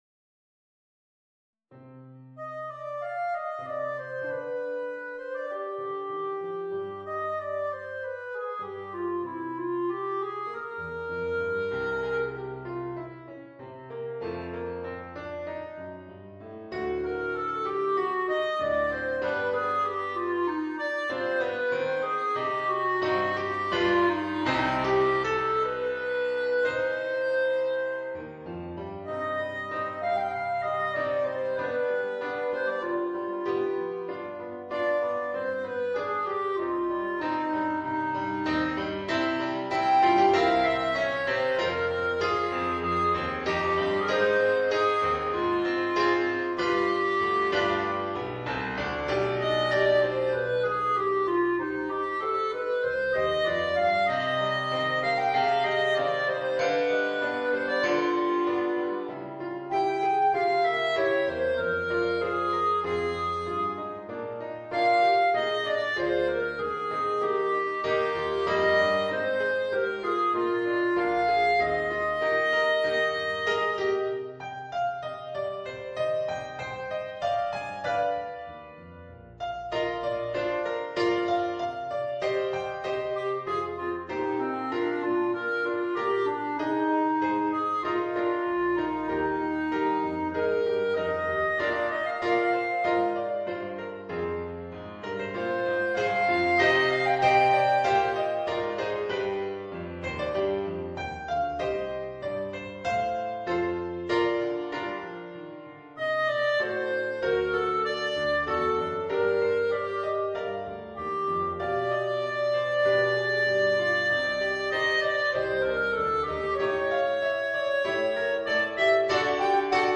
Voicing: Clarinet and Piano